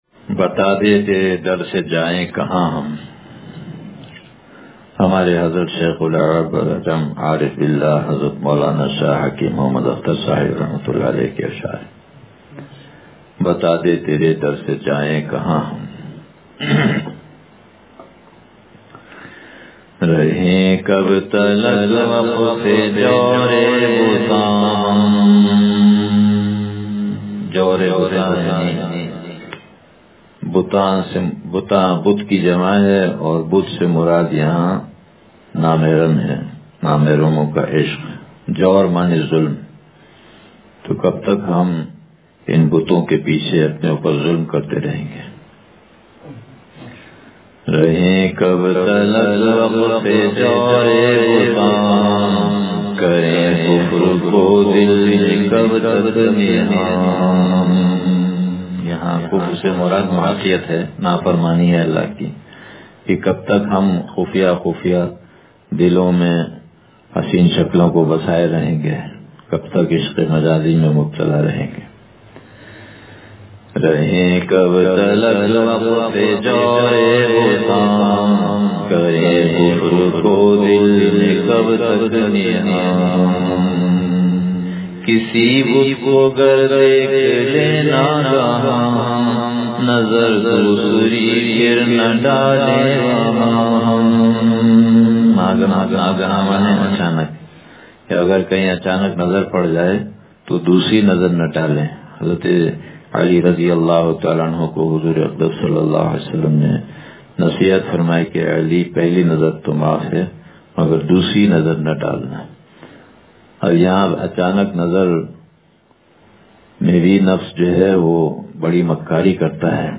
بتا دے ترے در سے جائیں کہاں ہم – مجلس بروز اتوار